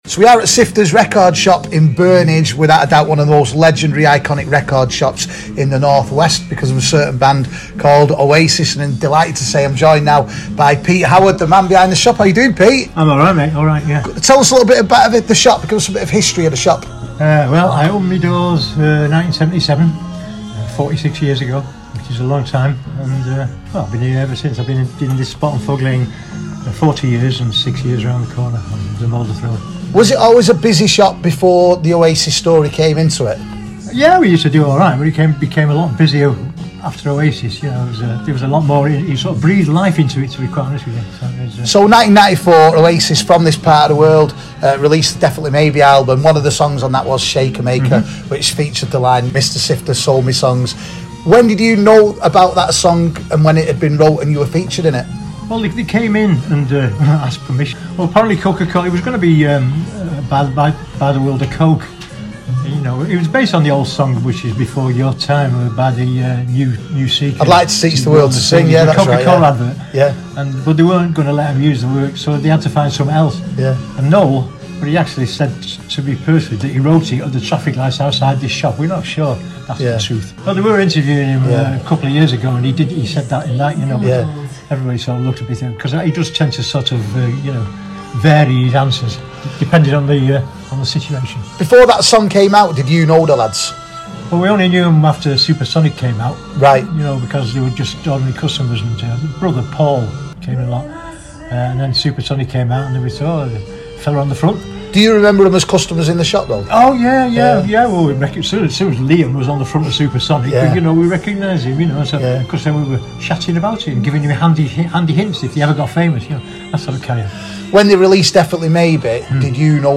INTERVIEW: The legacy of iconic record shop where Oasis used to visit